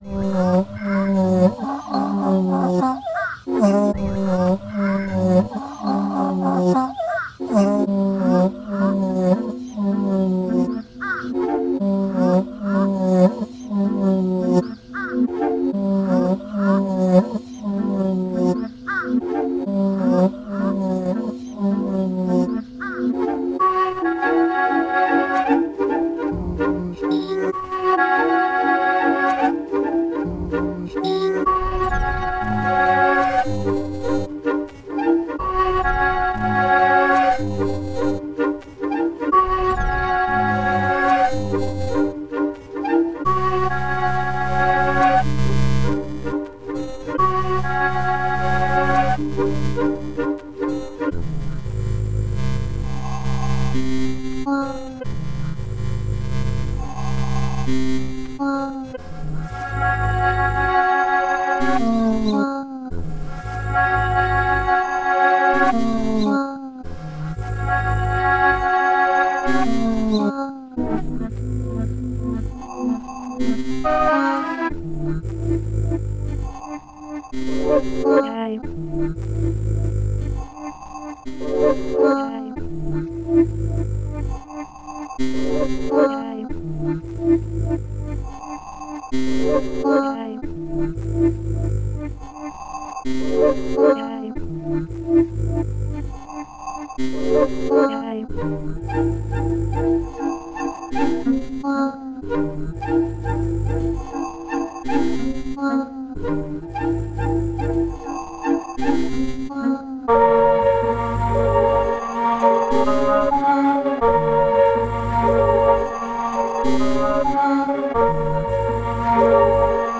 padanisa.py([VinylHorn,Csound,Hascillators,PlaintalkKathy,Etc],7min)